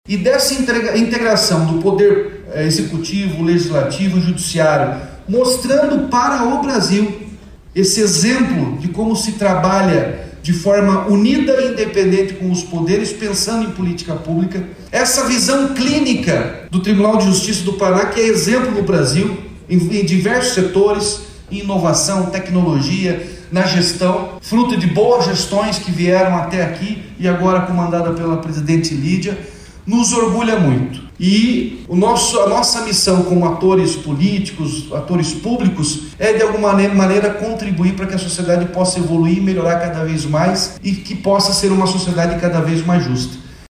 A cerimônia aconteceu na sede do Tribunal de Justiça do Paraná (TJPR), em Curitiba, e contou com a presença do governador Ratinho Junior (PSD) e de autoridades.
O governador Ratinho Junior (PSD) avaliou que a medida é uma importante ajuda para combater crimes do gênero.